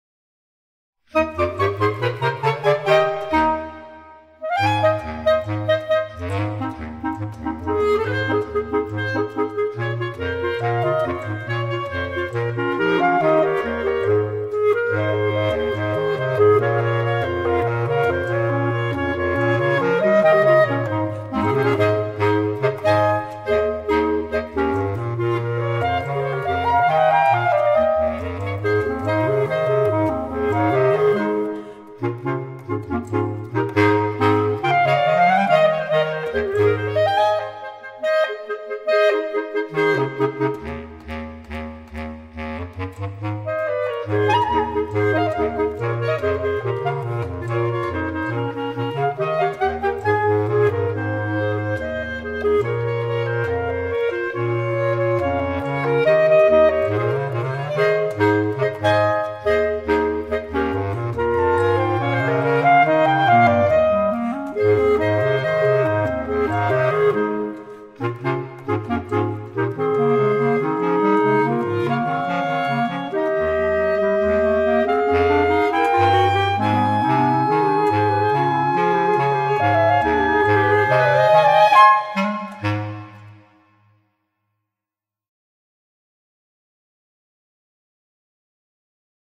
B♭ Clarinet 1 B♭ Clarinet 2 B♭ Clarinet 3 Bass Clarinet
单簧管四重奏
风格： 童谣
现在，这首人人皆知的歌曲以活泼可爱的单簧管四重奏形式呈现！